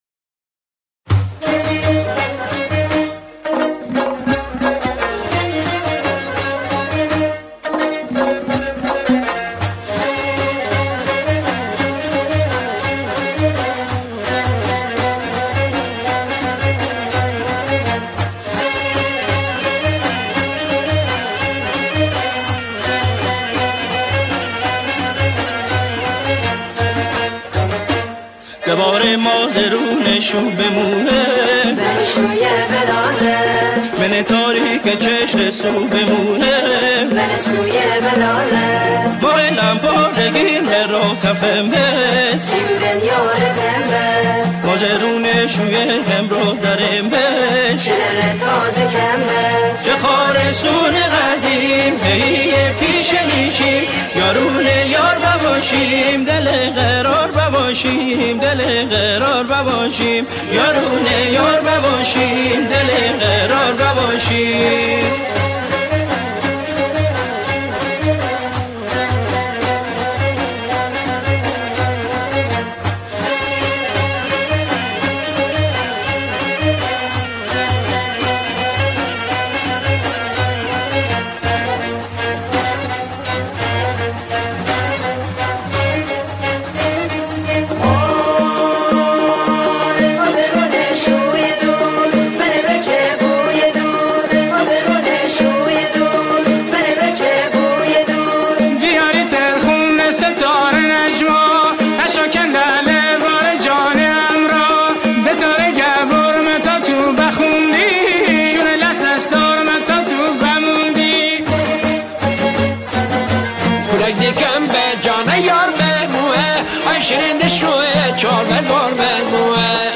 همخوانی شعری به گویش مازندرانی
گروهی از همخوانان